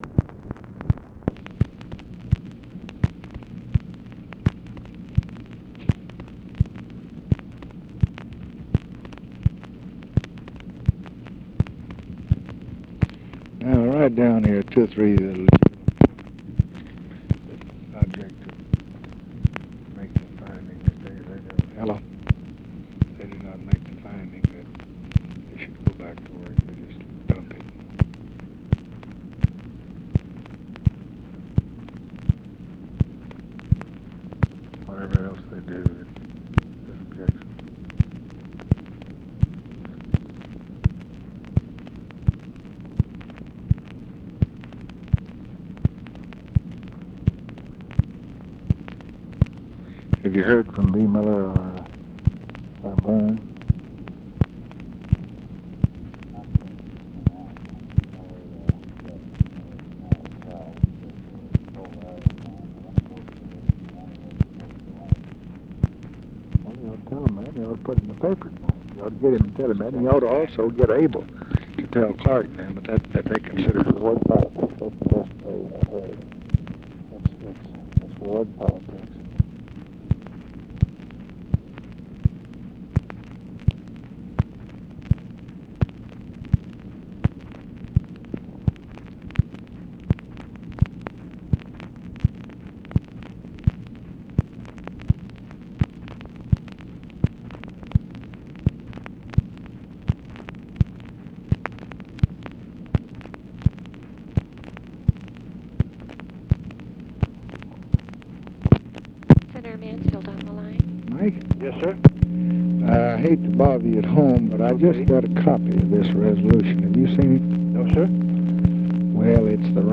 Conversation with MIKE MANSFIELD and OFFICE CONVERSATION, August 2, 1966
Secret White House Tapes